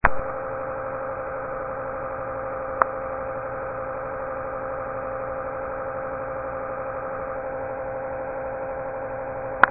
このHD-H160LAN、最近ずっと騒音がしてたんです。
かなりの音量でぶぉーんと鳴っていて、近くにしばらくいると頭が痛くなるほど。
ビットレートが低い上に他の音がない状態ので分かりづらいでしょうが（ゴトッという音はマイクをぶつけたときのノイズです）。
nasnoise.mp3